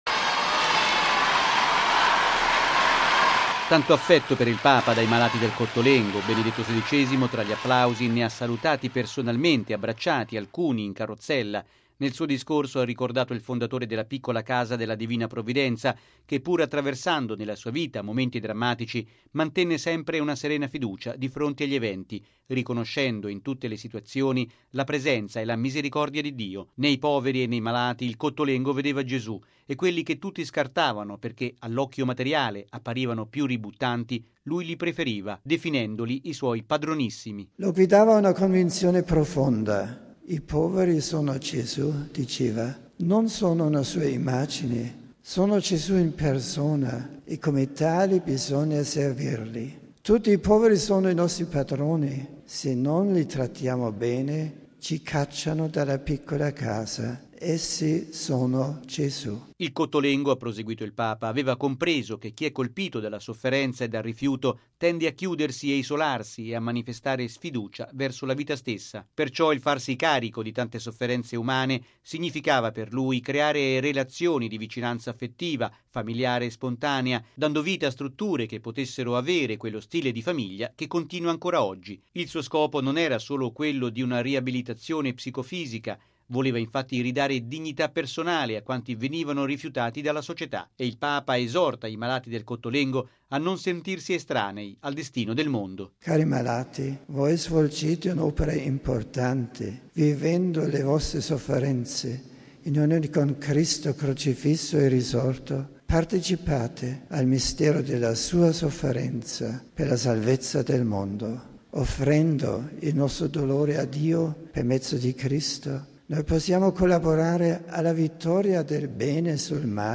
(applausi)